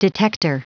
Prononciation du mot detector en anglais (fichier audio)
Prononciation du mot : detector